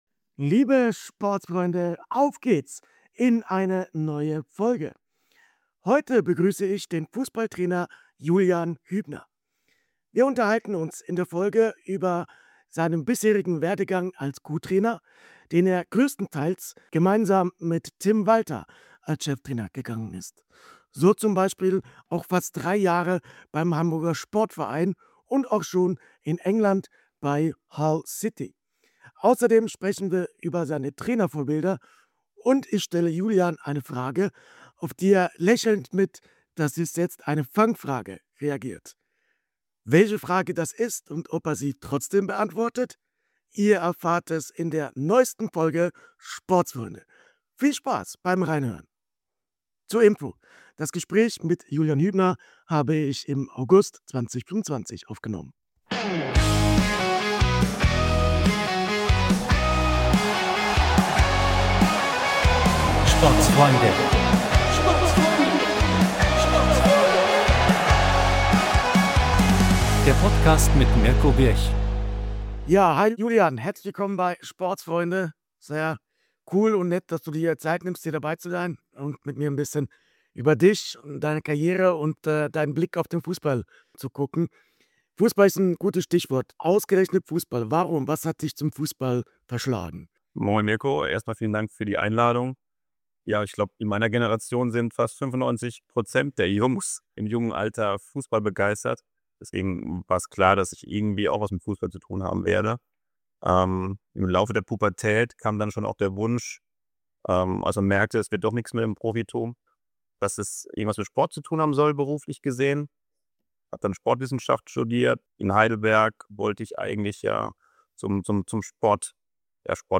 Copyright Intro & Outro Melodie